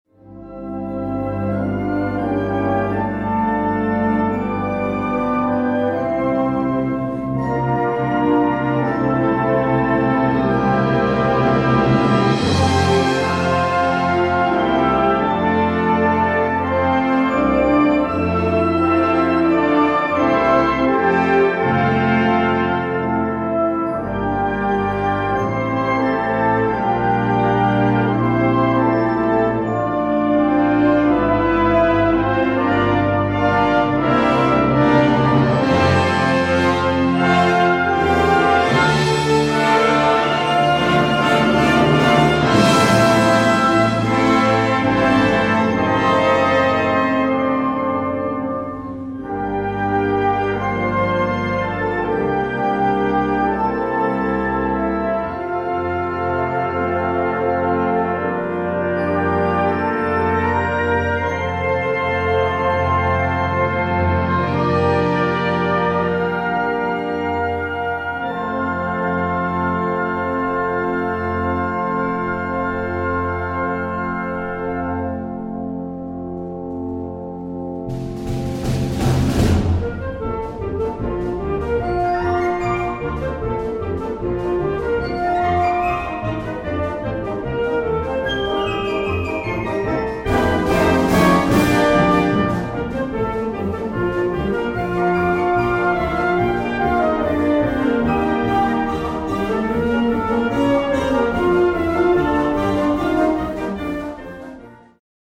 Gattung: Operette
Besetzung: Blasorchester